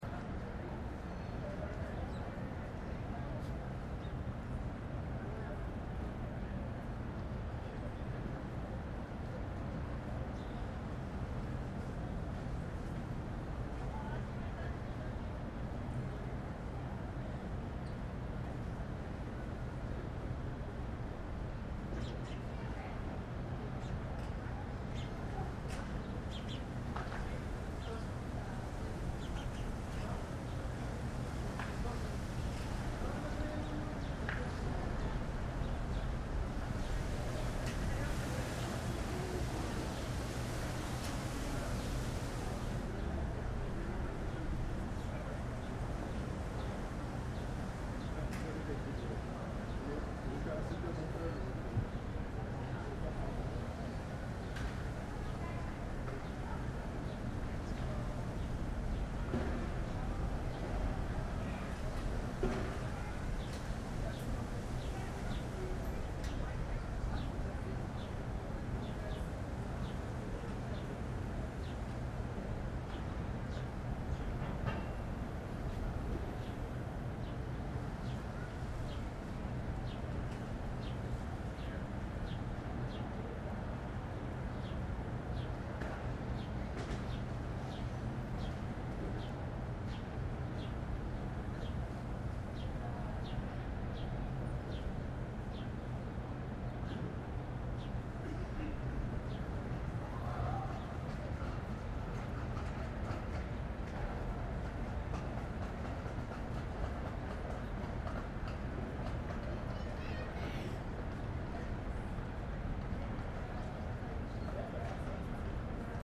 Шум городской улицы без машин в ясный день